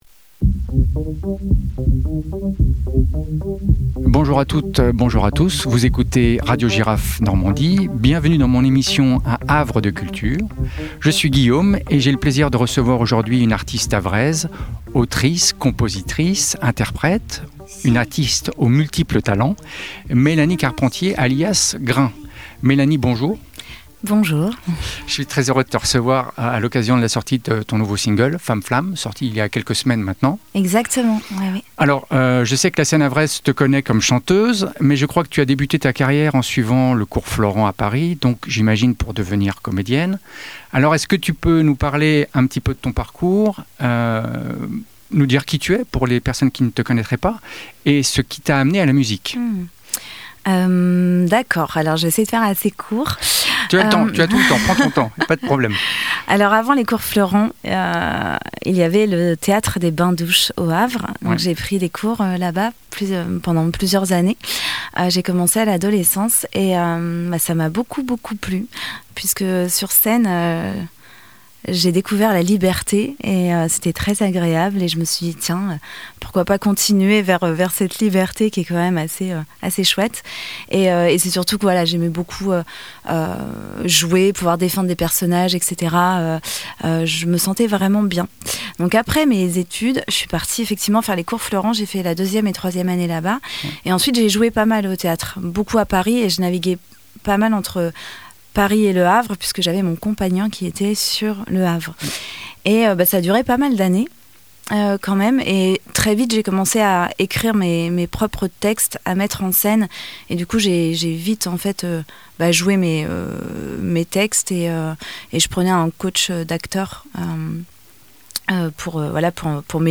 Une conversation ouverte et humaine sur le parcours d’une artiste qui prend le temps de faire les choses à sa manière.